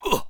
文件 文件历史 文件用途 全域文件用途 Enjo_dmg_01_1.ogg （Ogg Vorbis声音文件，长度0.3秒，209 kbps，文件大小：8 KB） 源地址:地下城与勇士游戏语音 文件历史 点击某个日期/时间查看对应时刻的文件。